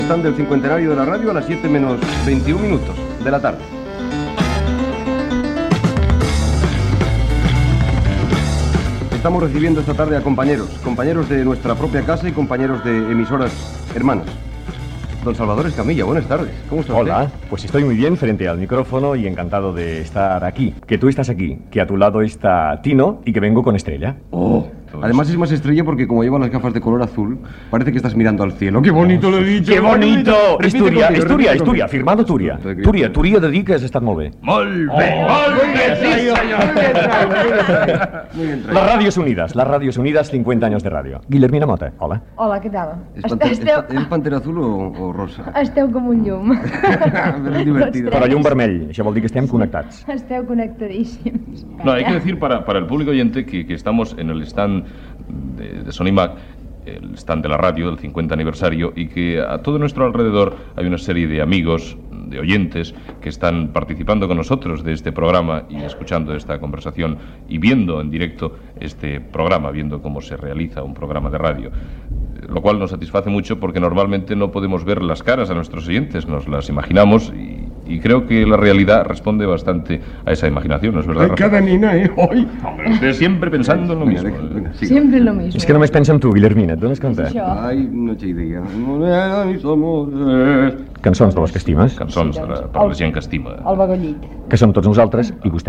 Programa especial fet des de la Fira Sonimag amb motiu dels 50 anys de Ràdio Barcelona, entrevista a Salvador Escamilla i la cantant Guillermina Motta.
Entreteniment